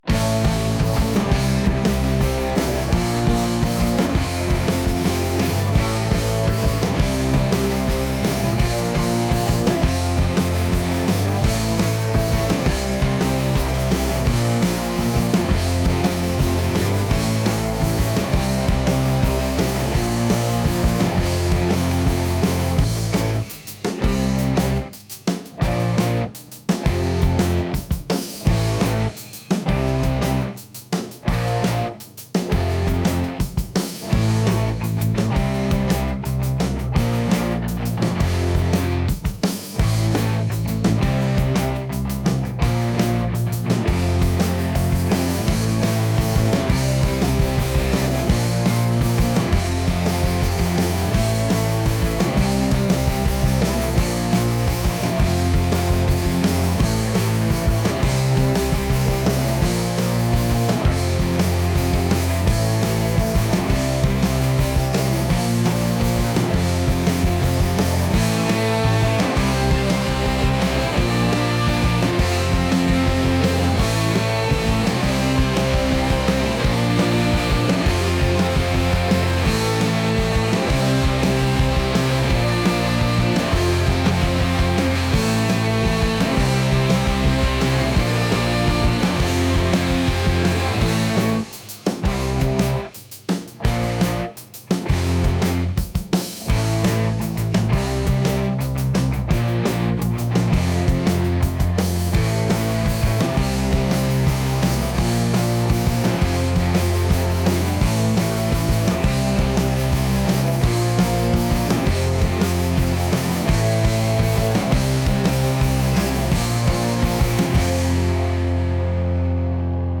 energetic | alternative | rock